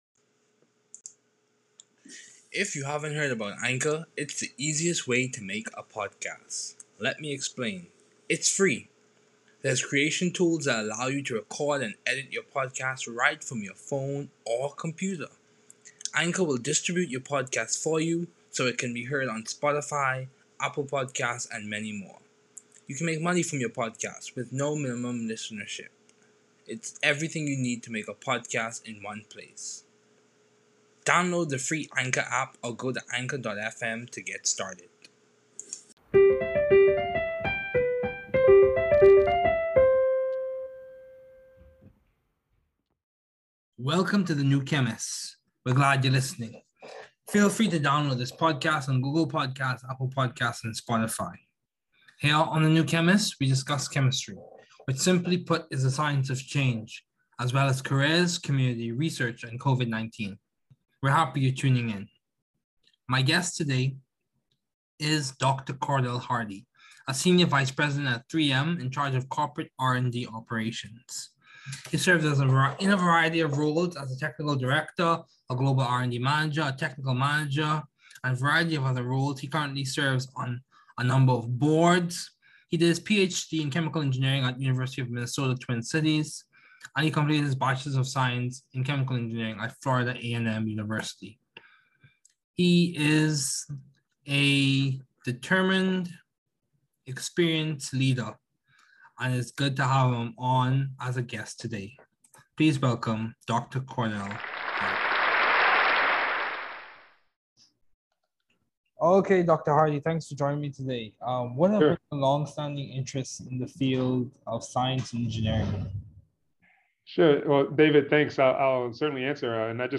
The New Chemist Podcast: Interview